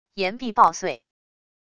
岩壁爆碎wav音频